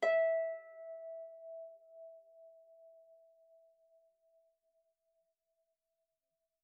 KSHarp_E5_mf.wav